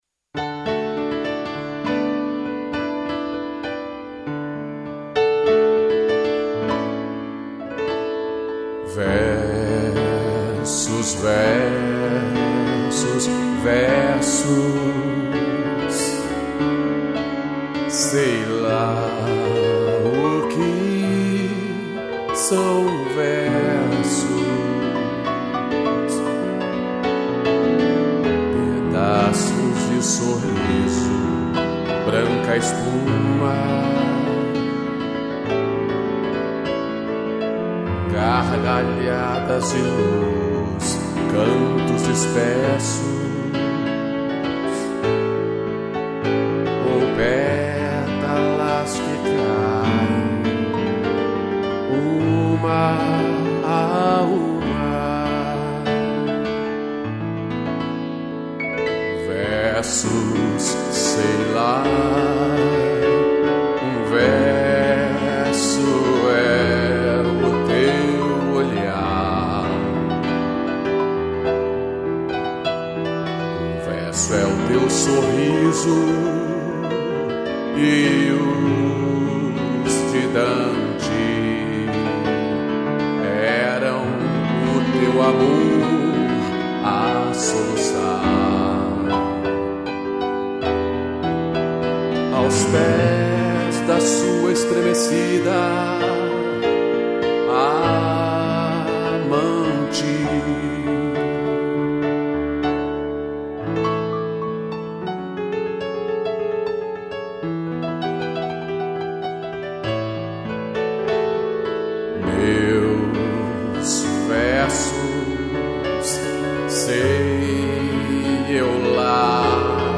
2 pianos e cello